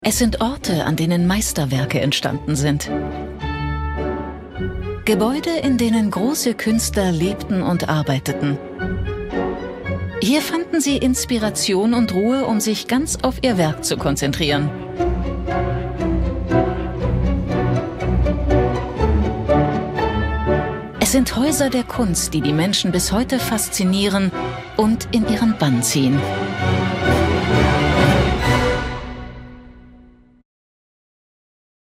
sehr variabel
Mittel minus (25-45)
Doku